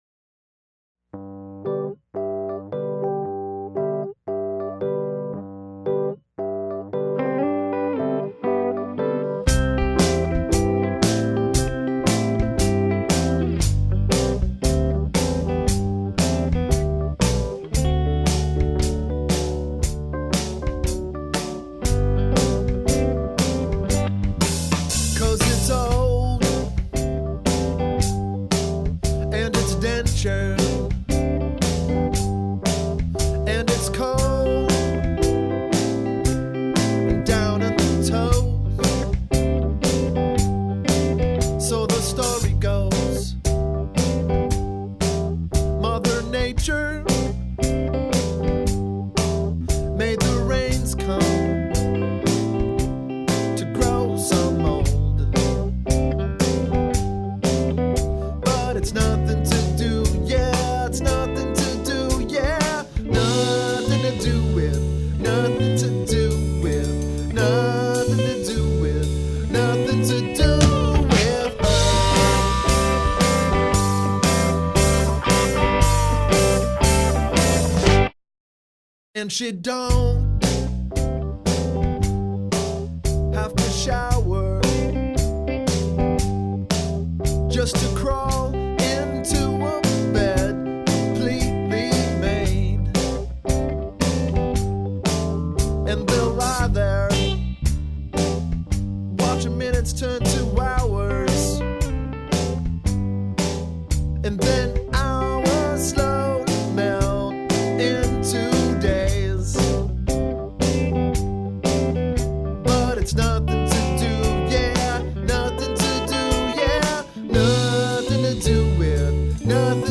in which delicate songwriting is interwoved by complex jams.
studio recordings